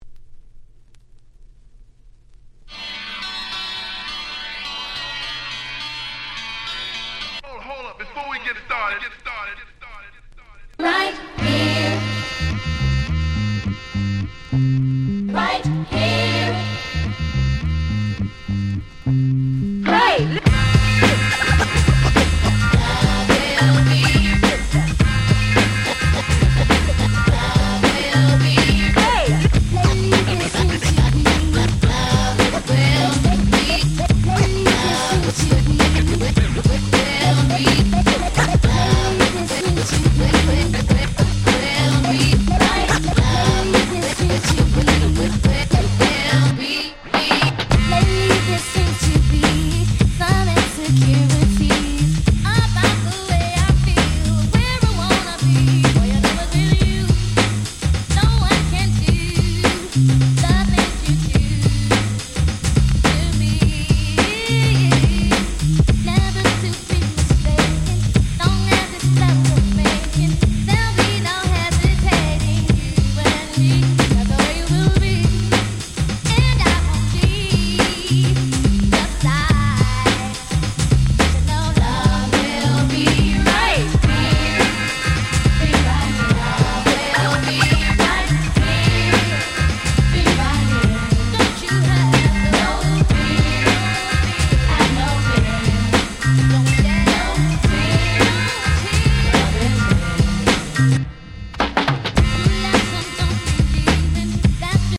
92' Super R&B Classics !!
割と激し目なScratchも相まって、良い意味で「いつもの」感の無い超格好良いRemixです！